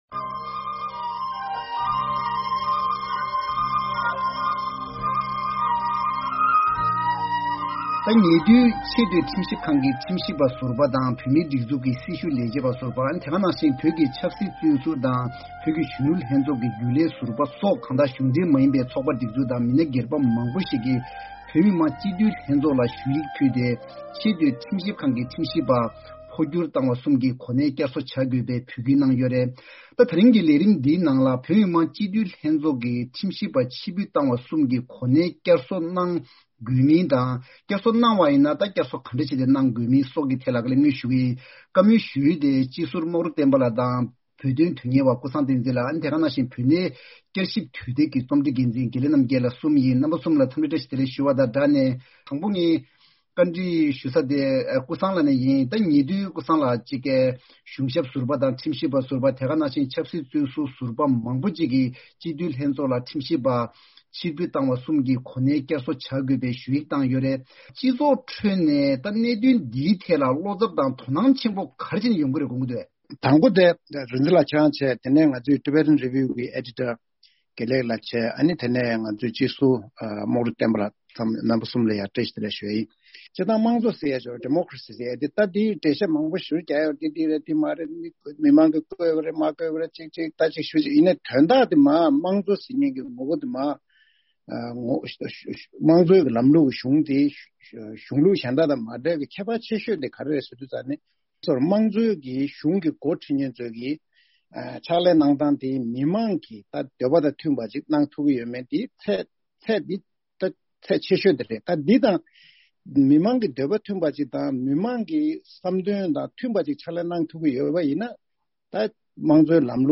༄༅། བདུན་ཕྲག་འདིའི་བགྲོ་གླེང་མདུན་ཅོག་ལས་རིམ་ནང་ཁྲིམས་ཞིབ་པ་འཕོ་འགྱུར་བཏང་བ་རྣམས་བསྐྱར་གསོ་གནང་དགོས་མིན་དང། བསྐྱར་གསོ་གནང་ཐབས་གང་ཡོད་པ་བཅས་ཀྱི་ཐད་གླེང་མོལ་ཞུ་ཡི་ཡིན།